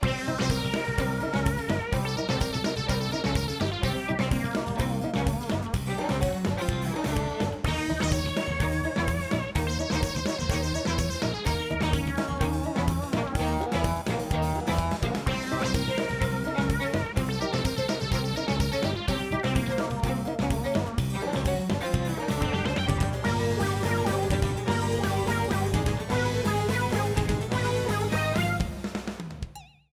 RIPPED FROM THE GAME
Fair use music sample